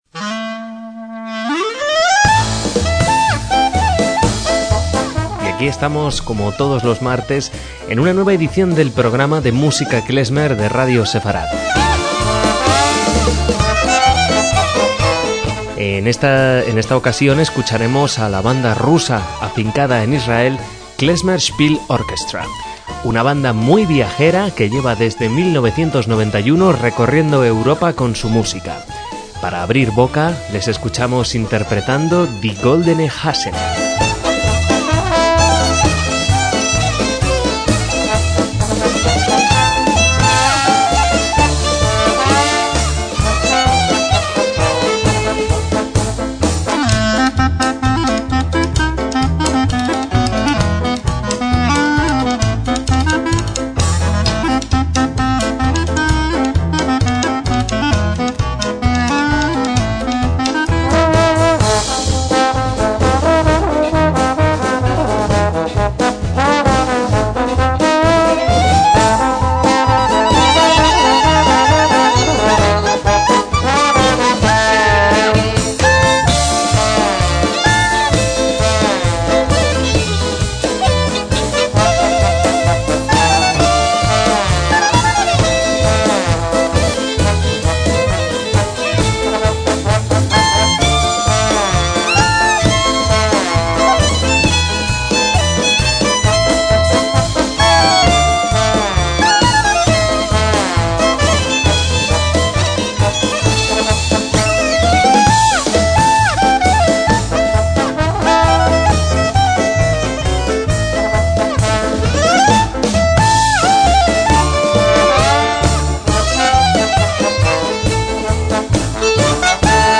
MÚSICA KLEZMER